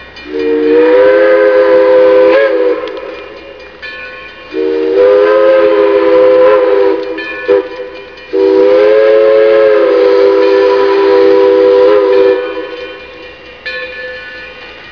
we hear #60 whistling for River Road while crossing the Raritain River